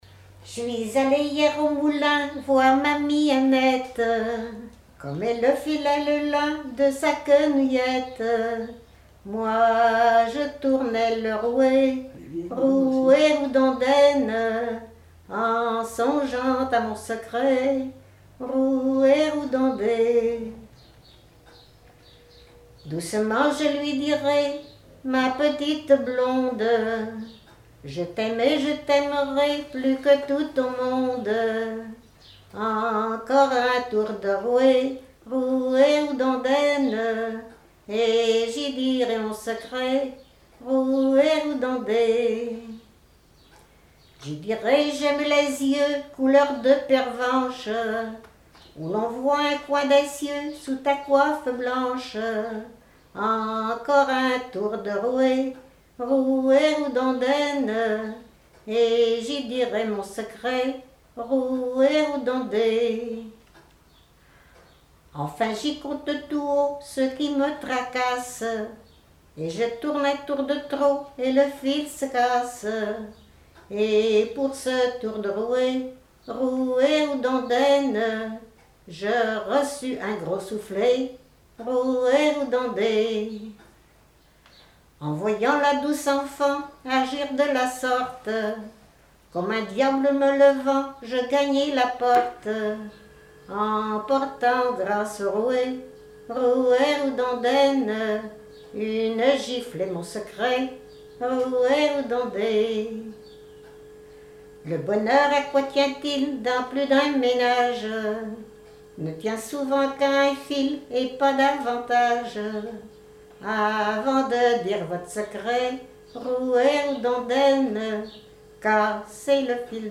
Genre strophique
Chansons et témoignages
Pièce musicale inédite